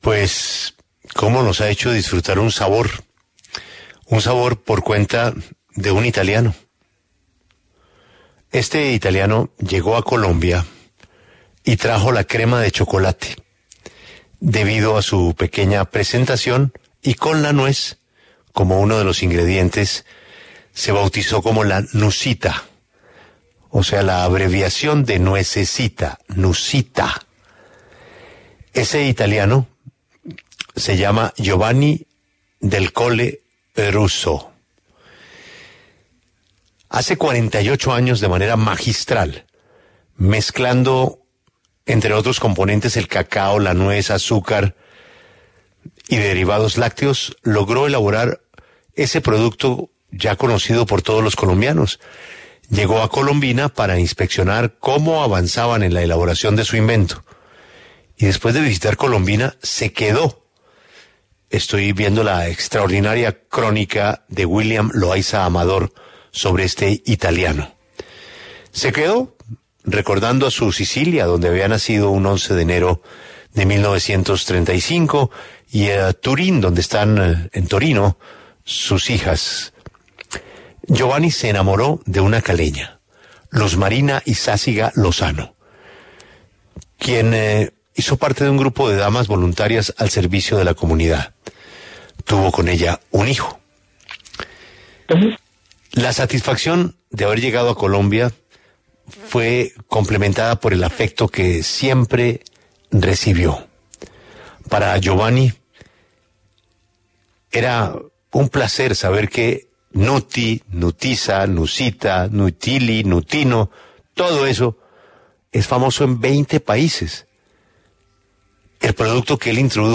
en los micrófonos de La W.